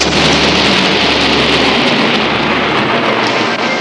Big Lightning Bolt Sound Effect
Download a high-quality big lightning bolt sound effect.
big-lightning-bolt.mp3